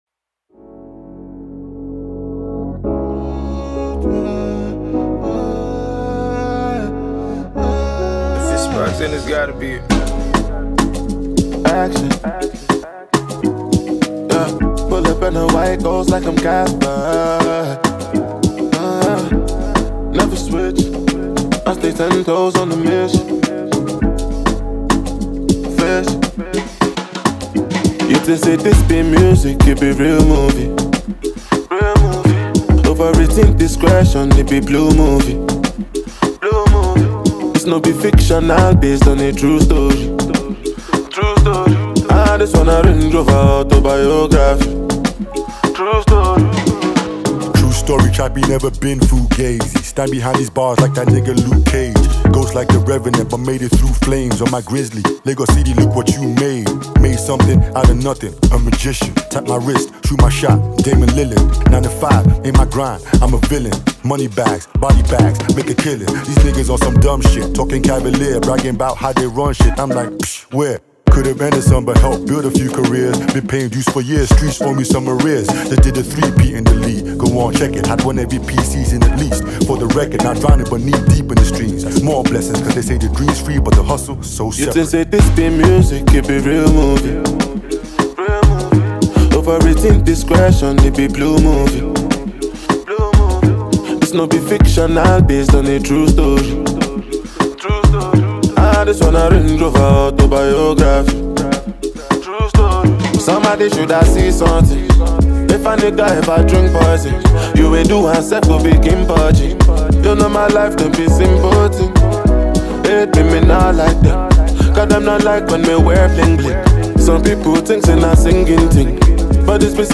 a Nigerian rap duo